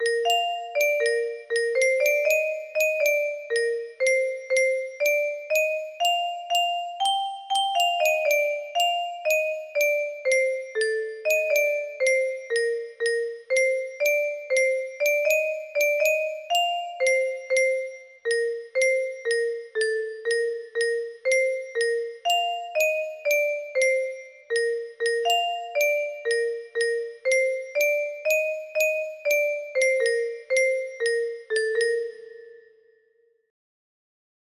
Cainen music box melody